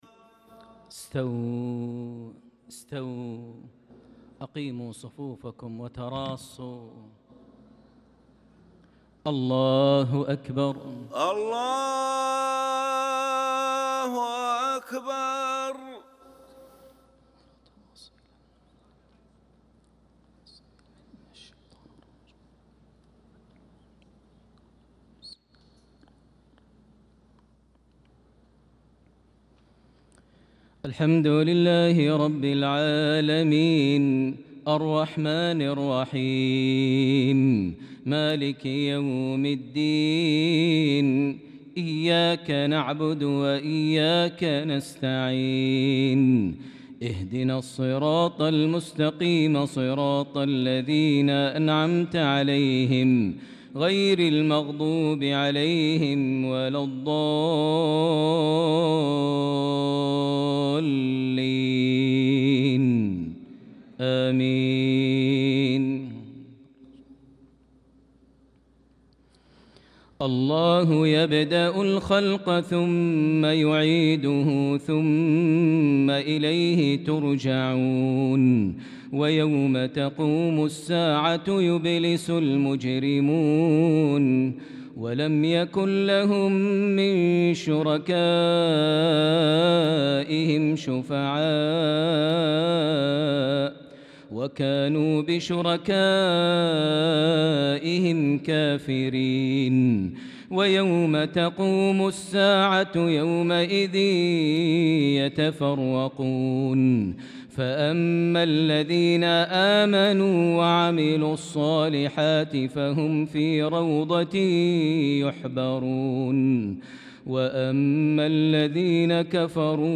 صلاة العشاء للقارئ ماهر المعيقلي 5 ذو القعدة 1445 هـ
تِلَاوَات الْحَرَمَيْن .